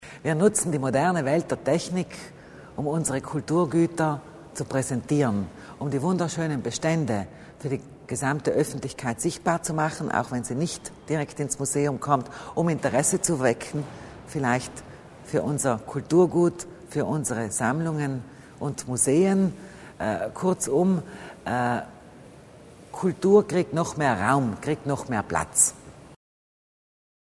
Landesrätin Kasslatter Mur zur Bedeutung des Projektes